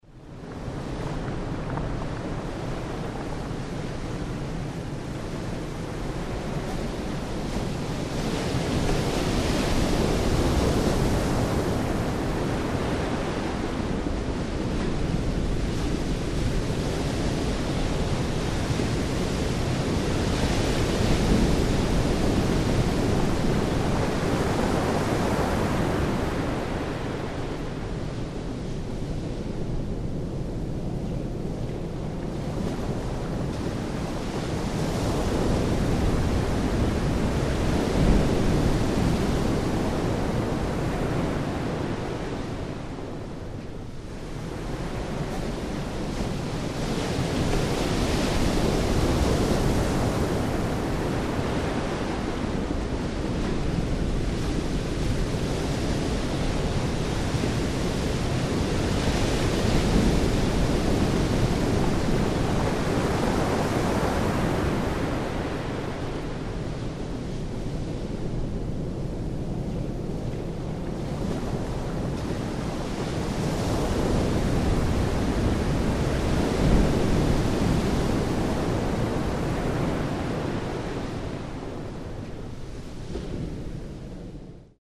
Звуки моря, океана
Шум океана у берега, гул прибоя